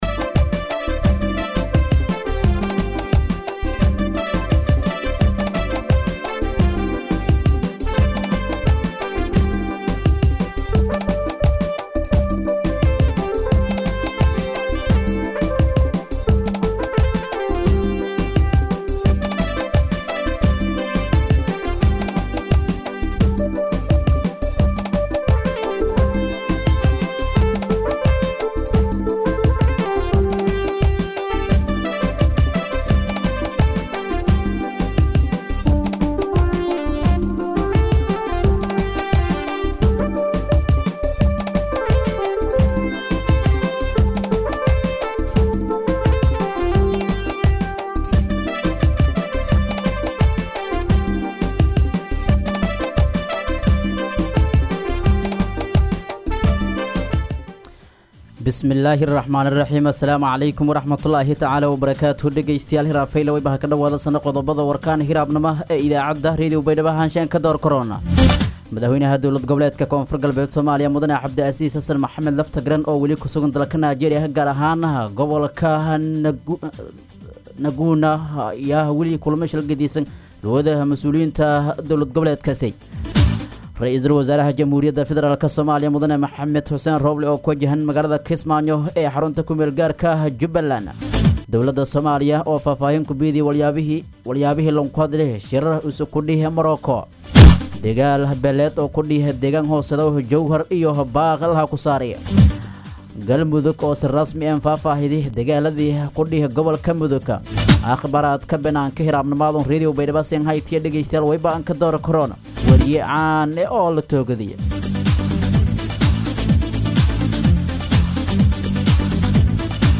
DHAGEYSO:- Warka Subaxnimo Radio Baidoa 7-7-2021